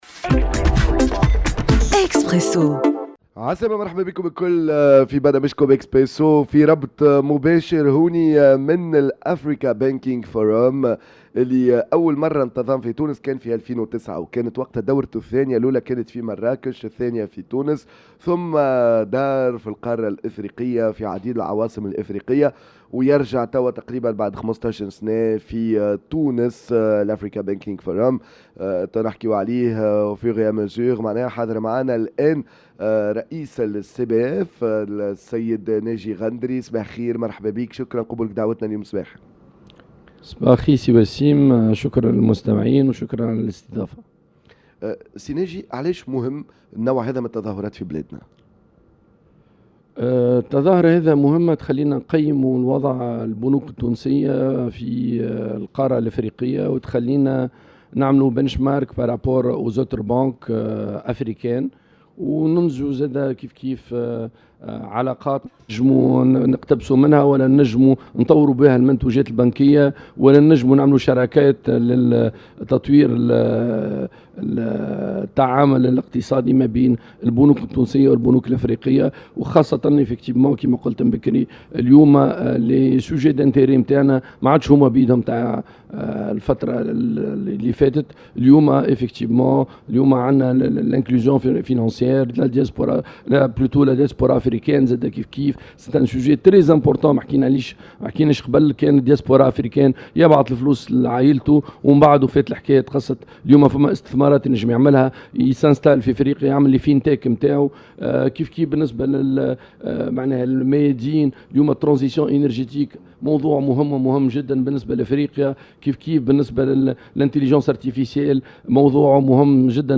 à l’occasion de la 16ème édition de l’Africa Banking Forum
dans un plateau spécial